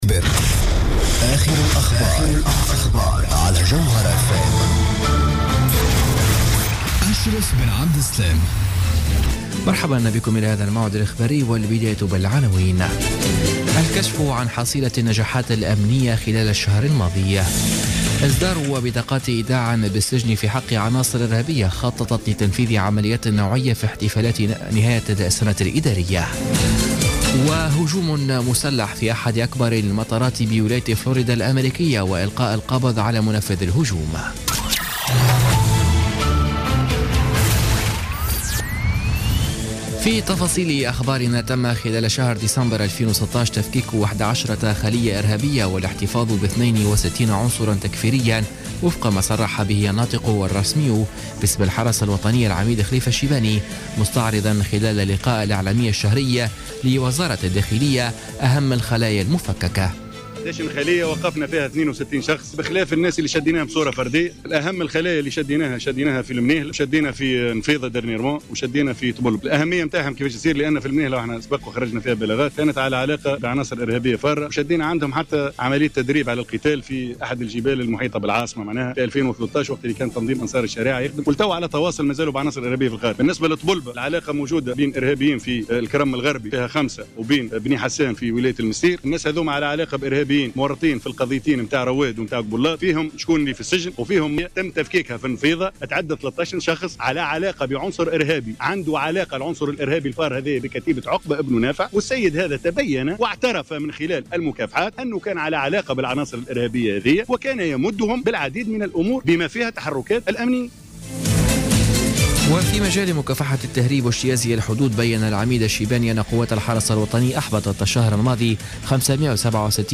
نشرة أخبار منتصف الليل ليوم السبت 7 جانفي 2017